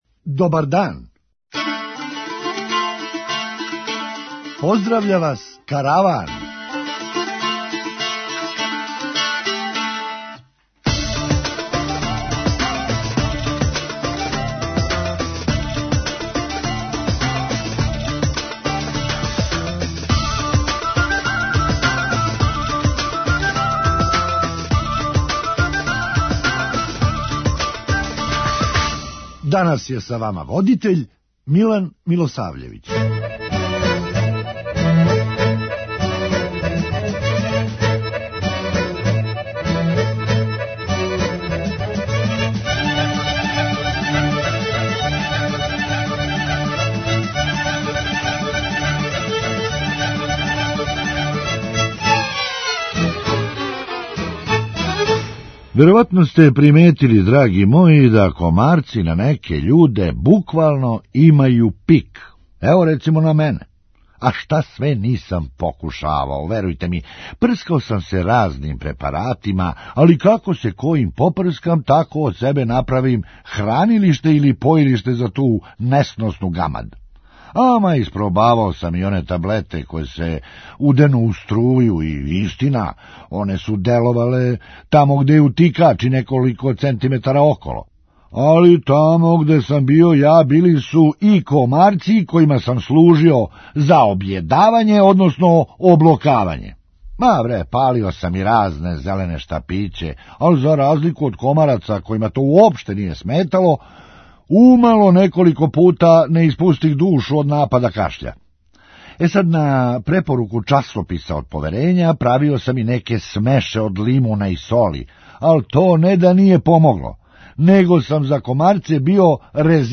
Хумористичка емисија
Али пошто је Амазон у питању, логичније би било да га зову Пирана. преузми : 8.87 MB Караван Autor: Забавна редакција Радио Бeограда 1 Караван се креће ка својој дестинацији већ више од 50 година, увек добро натоварен актуелним хумором и изворним народним песмама.